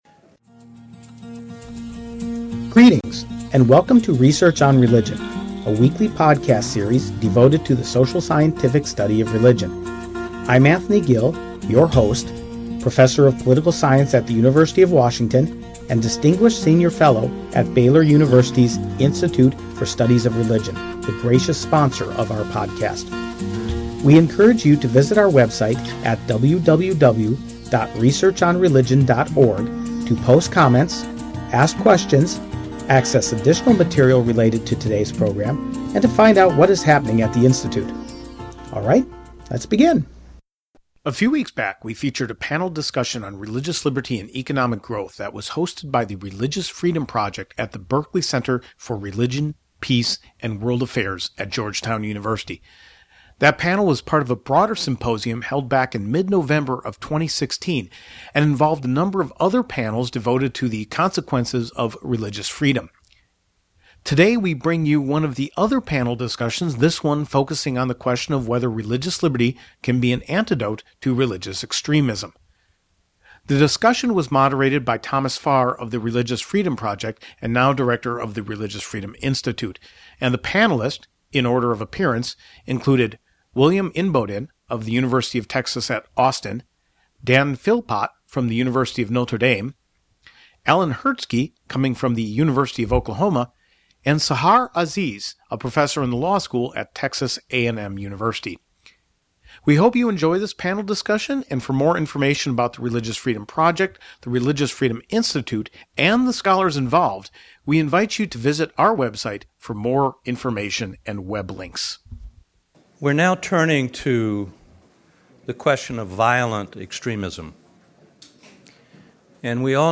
Can religious liberty serve as an antidote to religious violence extremism? This was the question posed to a panel of four scholars at a symposium sponsored by the Religious Freedom Project at the Berkeley Center for Religion, Peace, & World Affairs (Georgetown University).